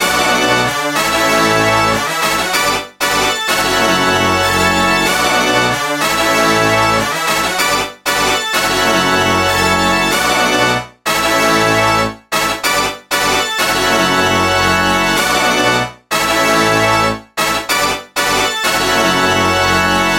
95 Bpm 灵魂次中音萨克斯
Am键，转弯。Am7 Fmaj7 Esus9 Am7
标签： 95 bpm Jazz Loops Brass Loops 3.40 MB wav Key : A
声道立体声